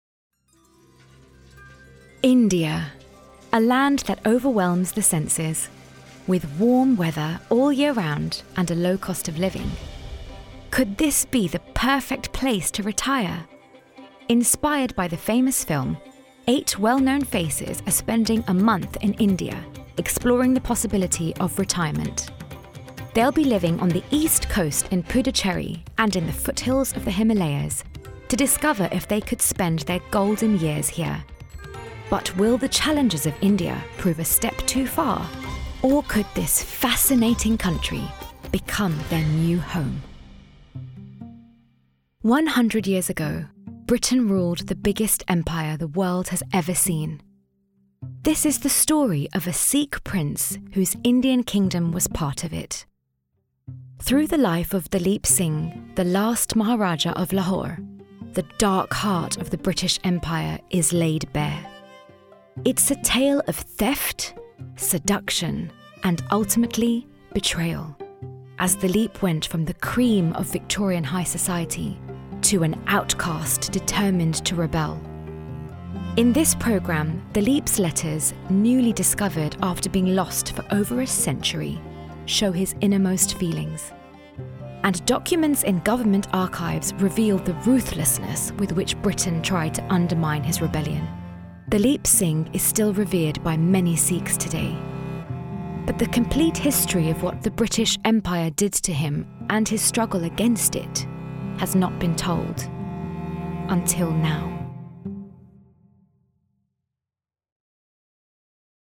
Singer, young, modern, warm, and fun to factual.
Commercial Audio